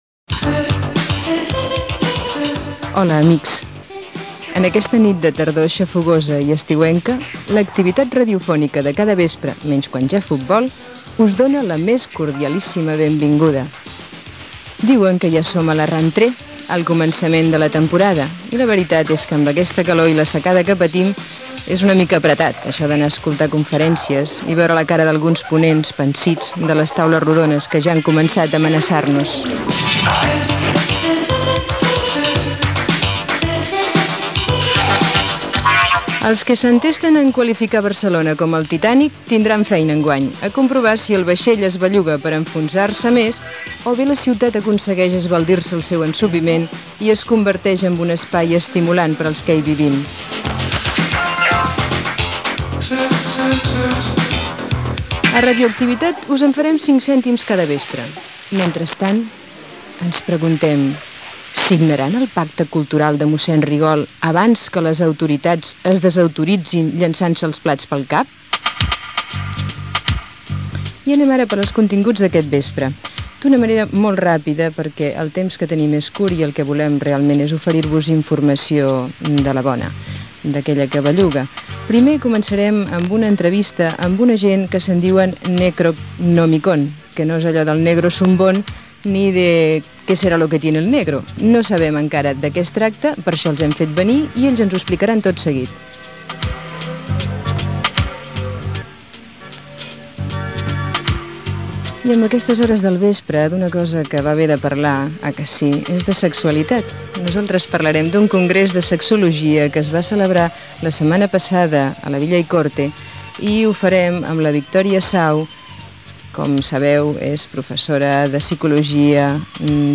Cultura
Presentador/a